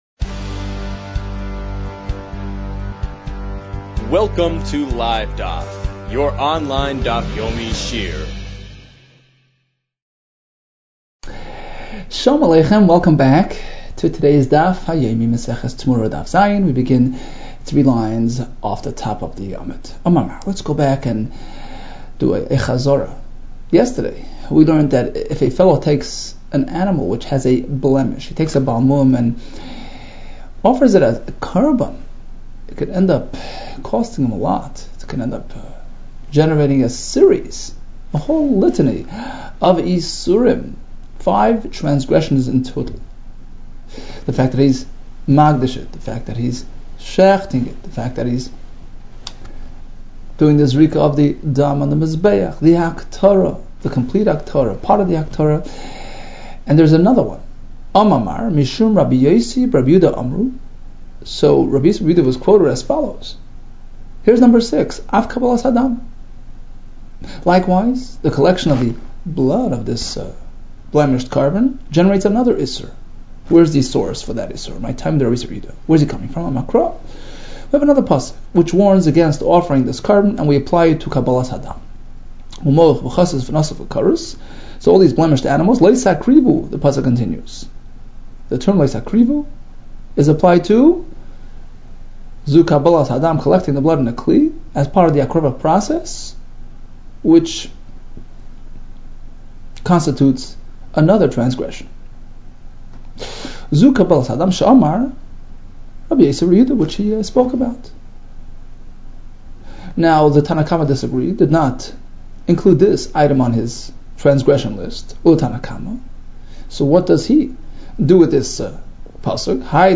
Temurah 6 - תמורה ו | Daf Yomi Online Shiur | Livedaf